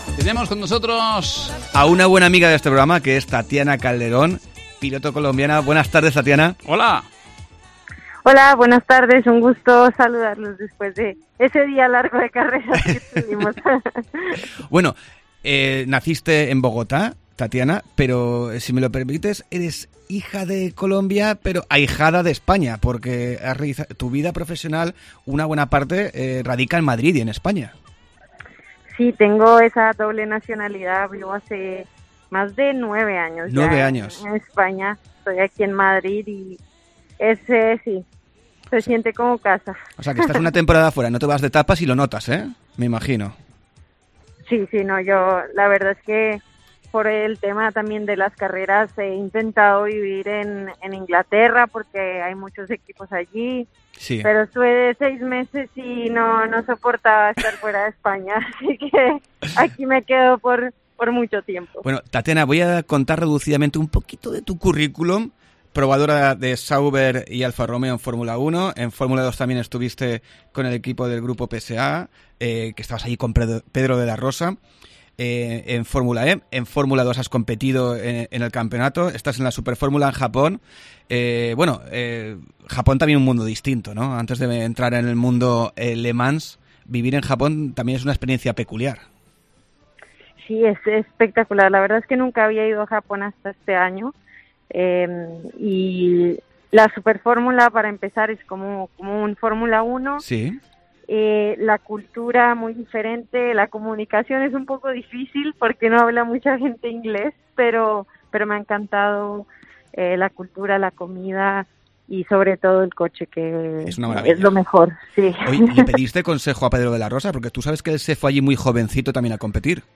AUDIO: Entrevista Luz de Cruce